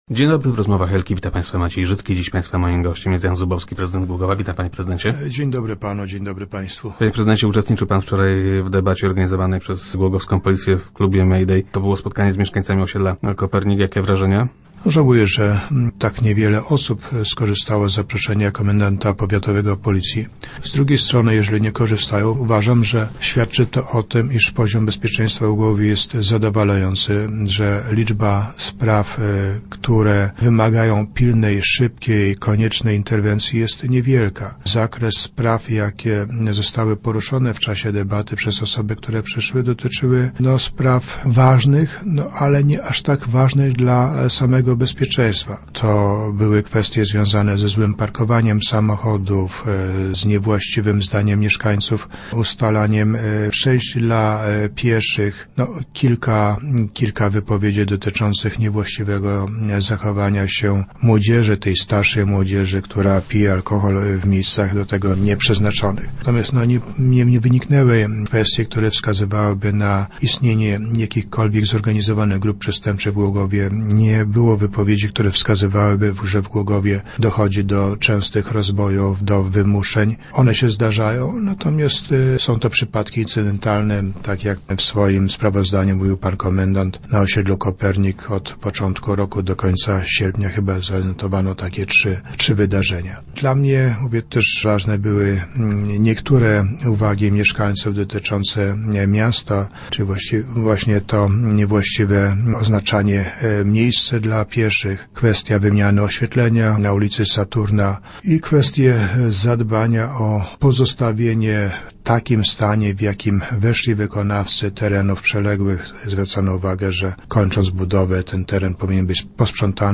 0511_zubowski_nowy.jpgPodczas debaty jaką głogowscy policjanci zorganizowali na osiedlu Kopernik okazało się, że największe problemy mieszkańców osiedla dotyczą oznakowania dróg i niezdyscyplinowanych kierowców. - Myślę, że świadczy to o tym, że poziom bezpieczeństwa w mieście jest zadowalający – mówił prezydent Jan Zubowski, który był gościem Rozmów Elki.